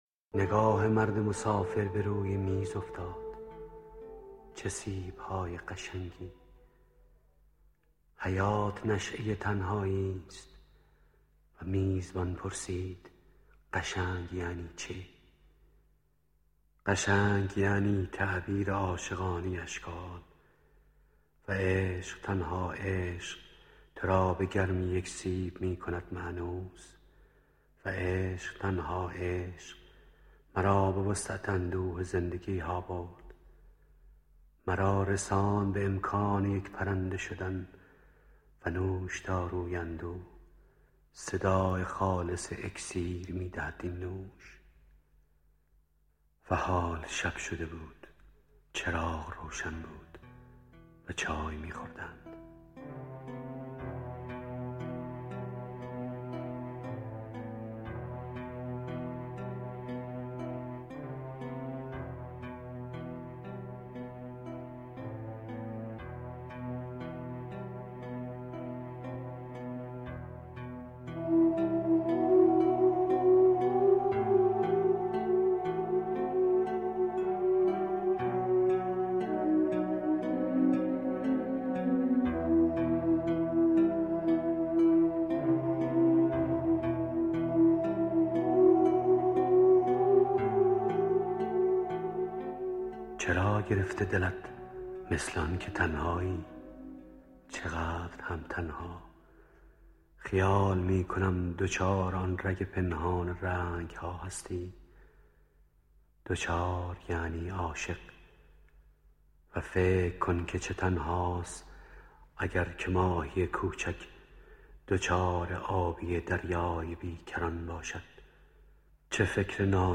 مسافر (با صدای احمدرضا احمدی)
صوت مسافر (با صدای احمدرضا احمدی) از شاعر سهراب سپهری در نشریه وزن دنیا